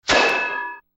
Hit